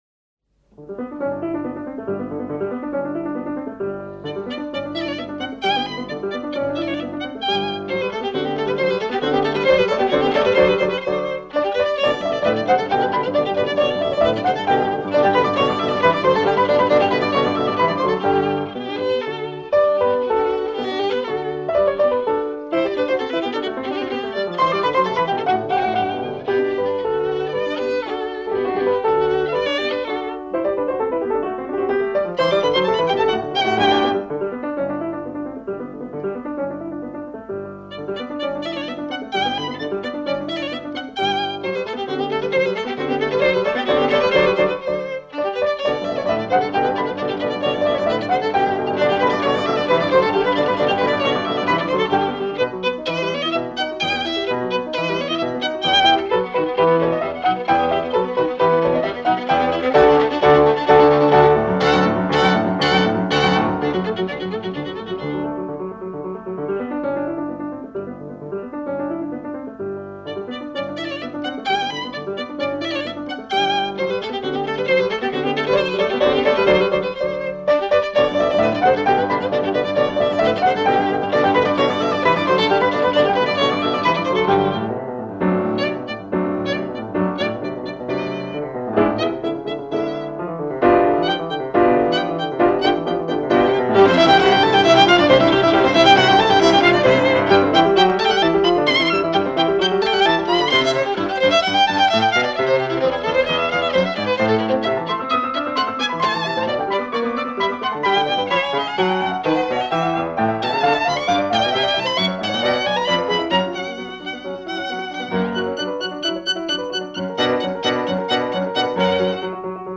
乐曲为a大调，有三个乐章。
她的演奏纯净、优雅，富于情感，体现了典型的匈牙利小提琴学校风格。